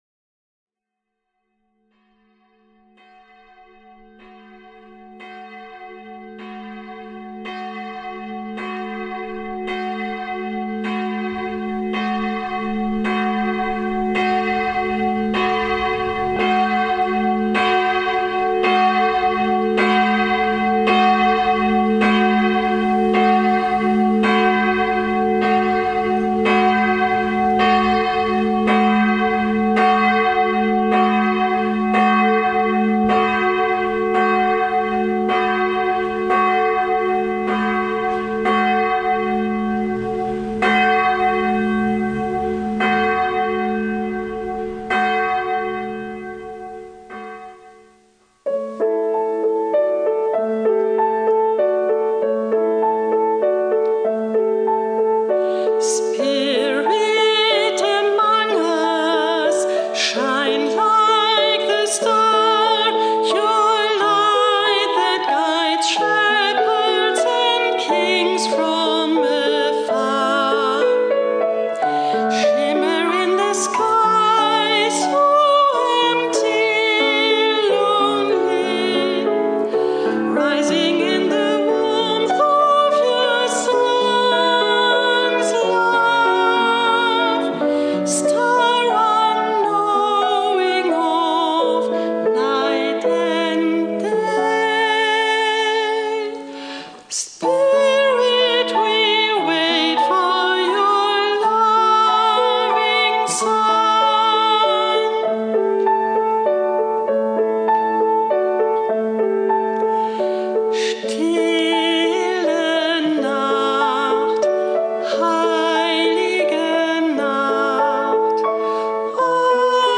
Gottesdienst am 21.07.2024, 10:00 Uhr
Hier finden Sie den Gottesdienst am 21.07.2024 um 10:00 Uhr.